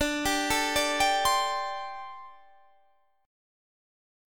D7sus4 Chord
Listen to D7sus4 strummed